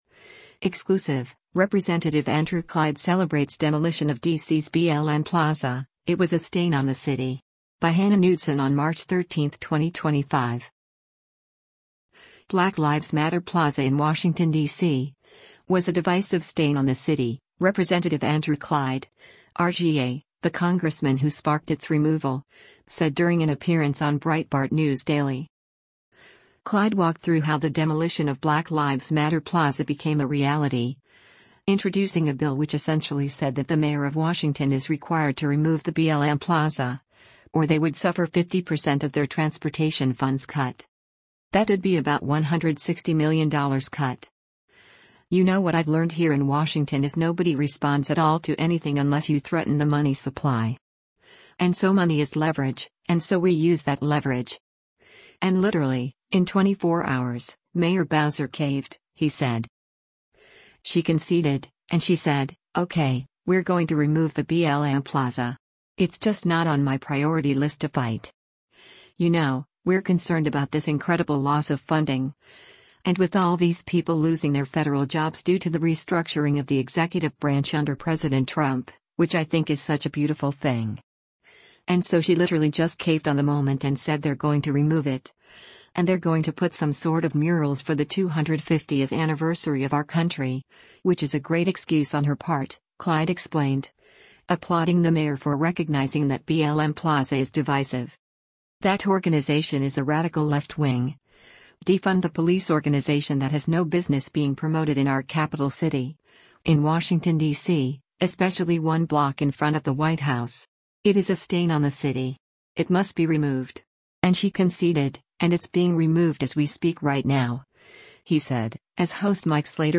Black Lives Matter Plaza in Washington, D.C., was a divisive “stain” on the city, Rep. Andrew Clyde (R-GA), the congressman who sparked its removal, said during an appearance on Breitbart News Daily.
Breitbart News Daily airs on SiriusXM Patriot 125 from 6:00 a.m. to 9:00 a.m. Eastern.